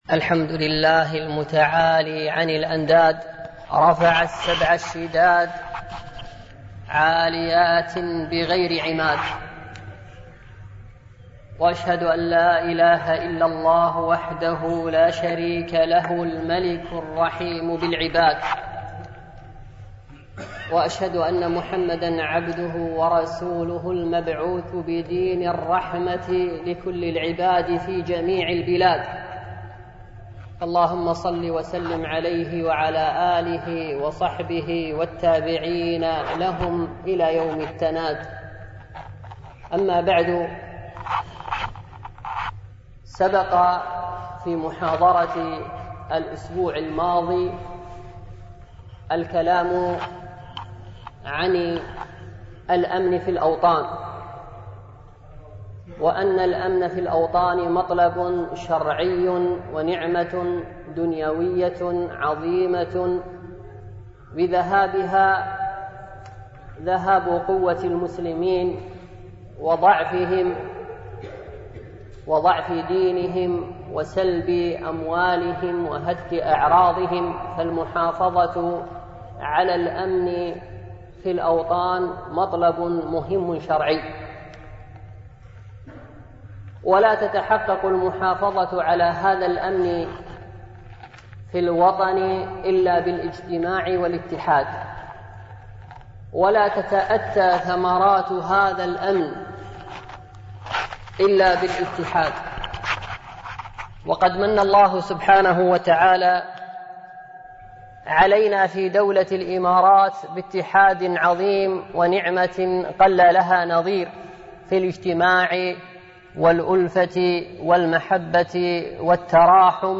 سلسلة محاضرات الأمن ومحاربة التطرف
MP3 Mono 22kHz 32Kbps (CBR)